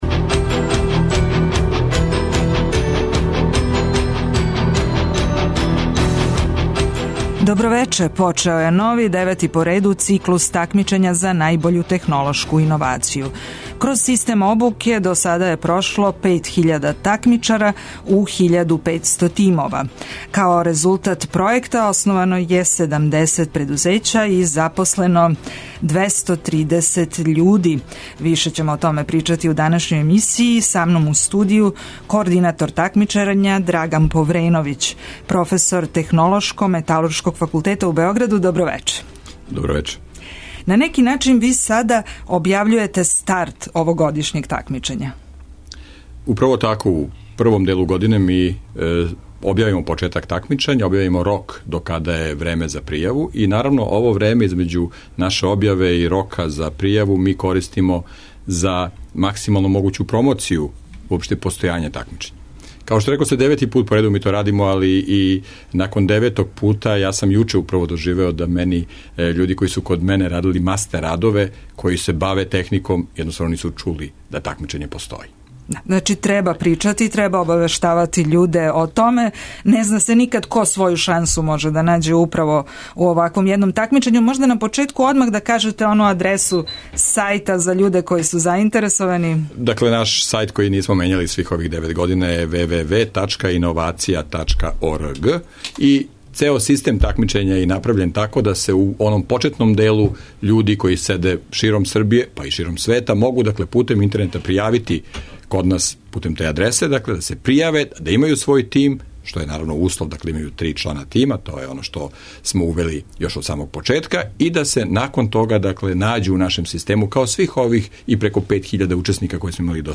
путем телефона укључићемо и прошлогодишње победнике и такмичаре, али и слушаоце који желе да поставе питања нашем госту.